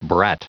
Prononciation du mot brat en anglais (fichier audio)
Prononciation du mot : brat